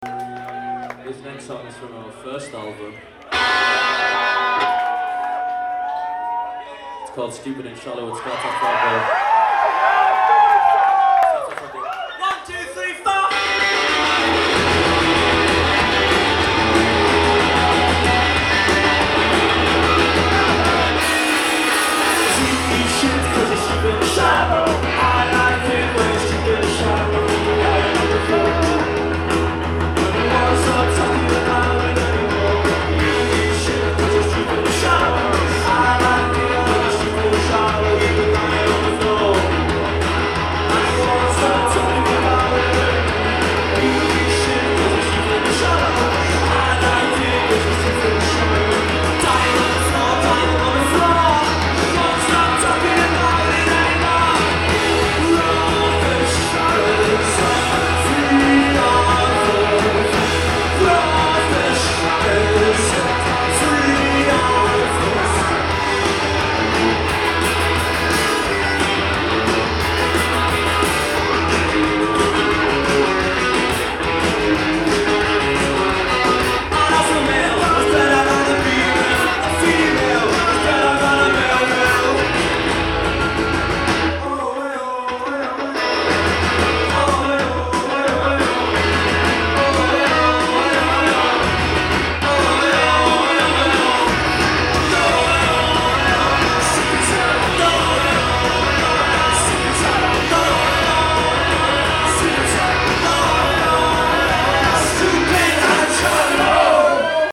Live at The Paradise